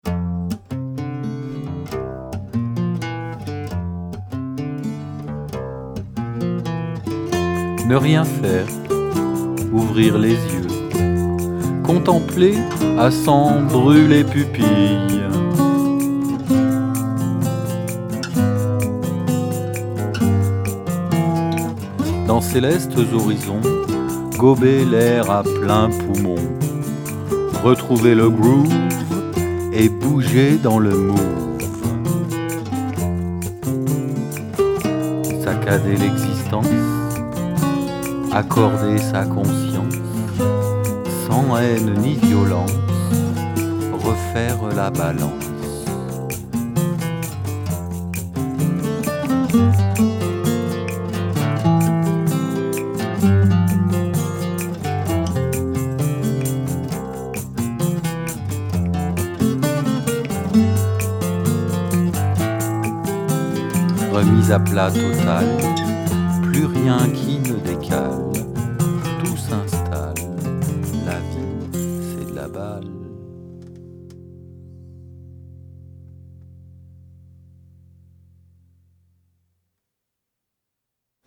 En duo